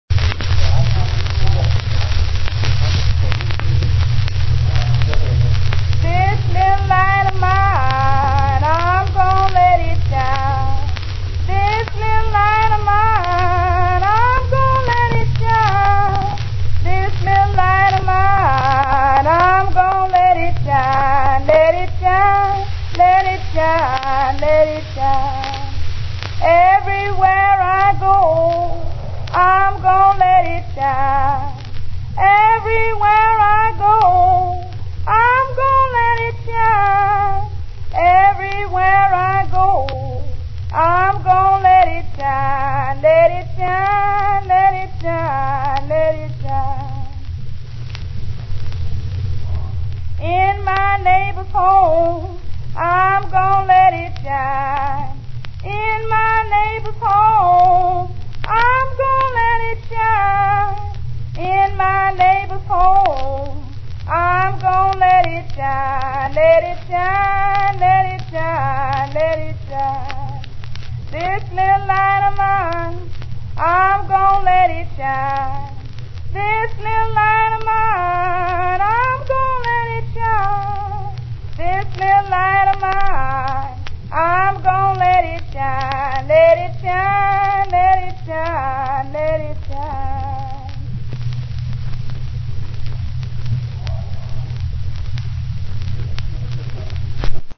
Note: The four verses in this song are sung one after the other. After each opening line, the phrase "I'm gonna let it shine" is added.